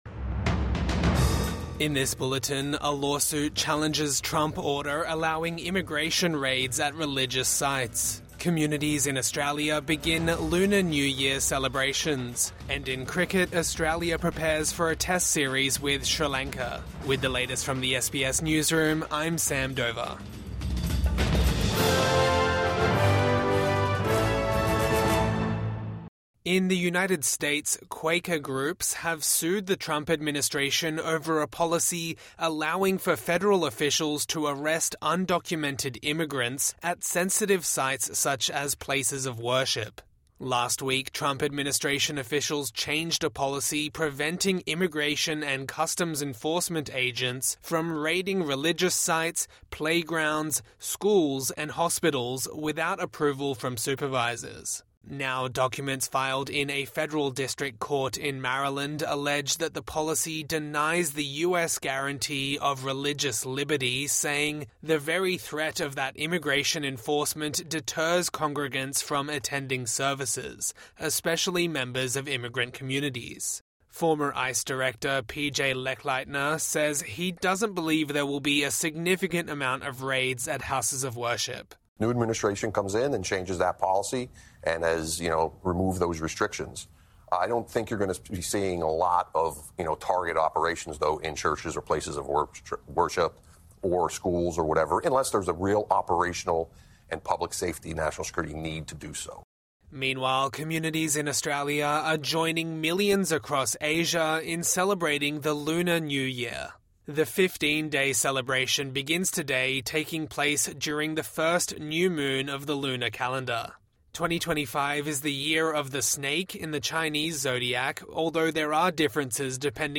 Midday News Bulletin 28 January 2025